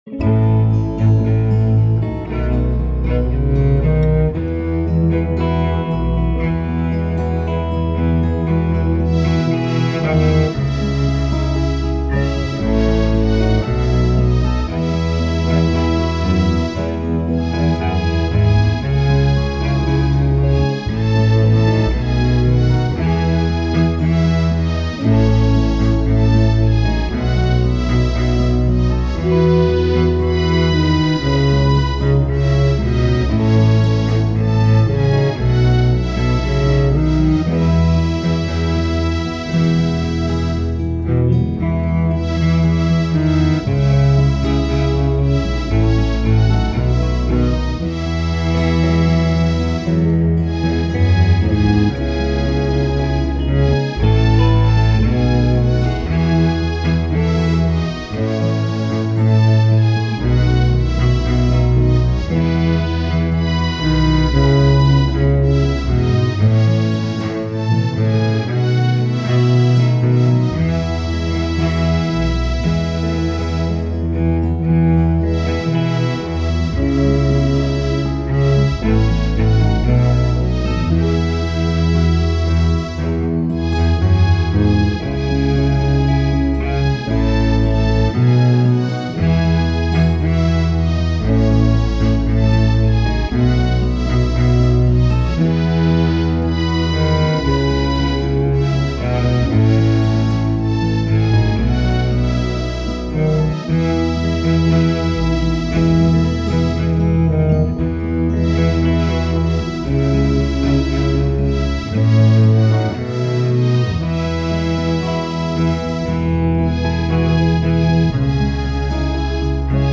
This is a nice relaxed setting.